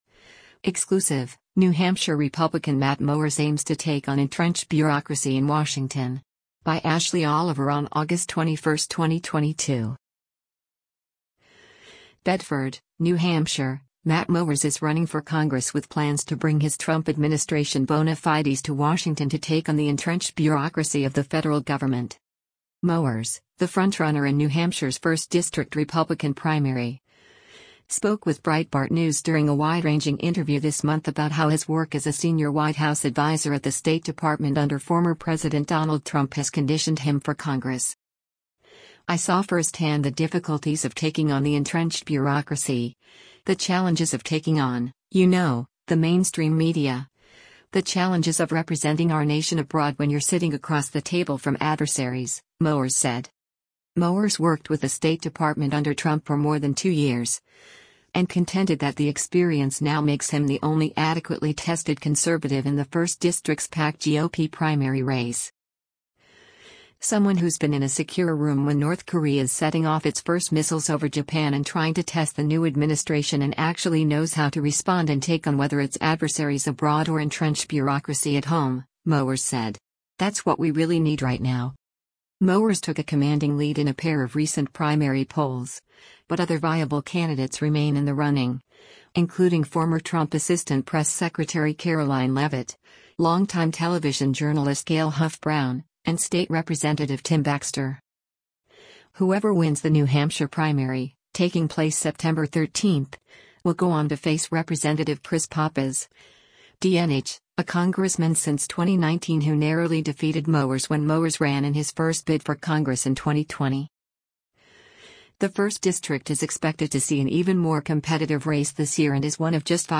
spoke with Breitbart News during a wide-ranging interview this month about how his work as a senior White House adviser at the State Department under former President Donald Trump has conditioned him for Congress.